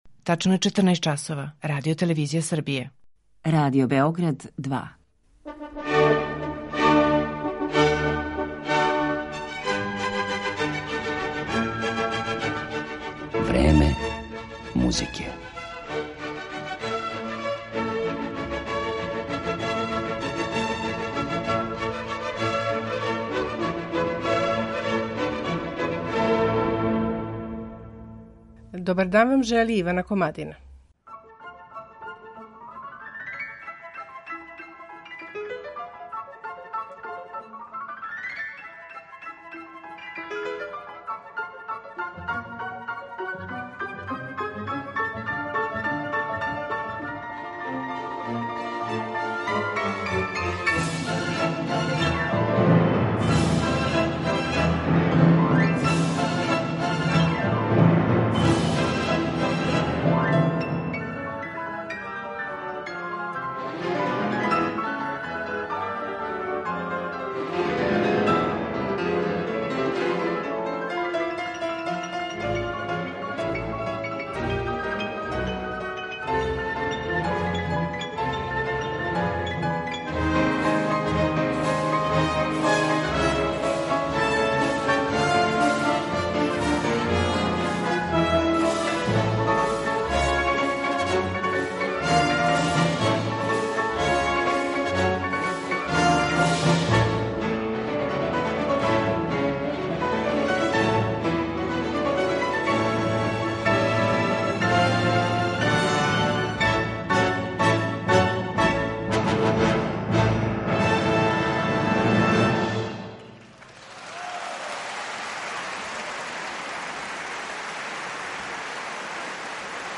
Катија Буниатишвили, клавир
Запрепашћујући виртуозитет пијанисткиње